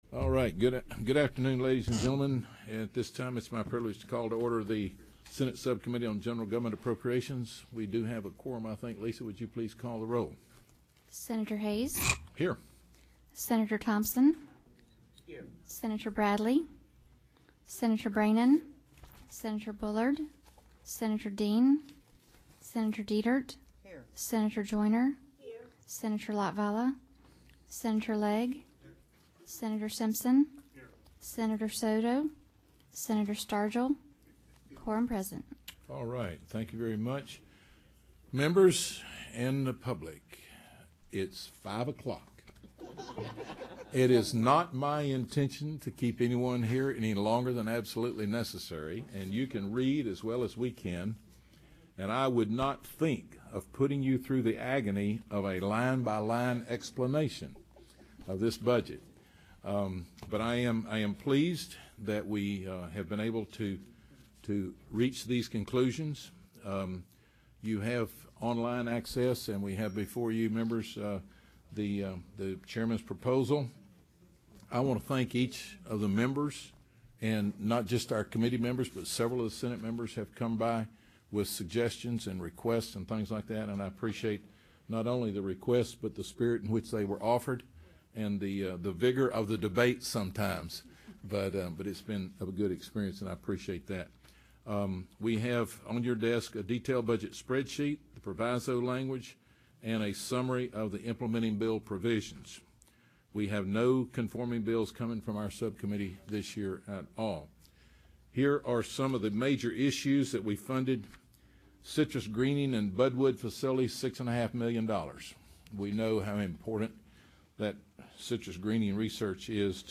Location: 110 Senate Office Building